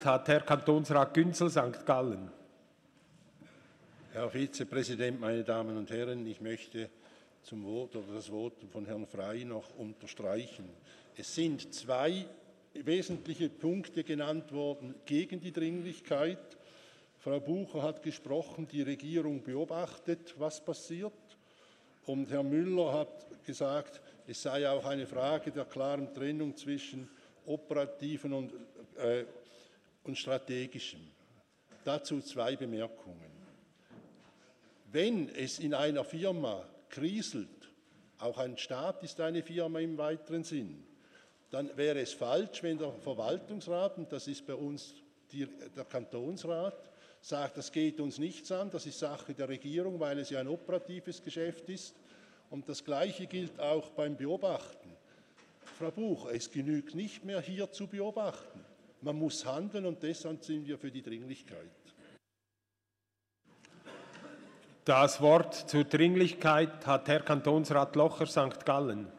Session des Kantonsrates vom 23. und 24. April 2019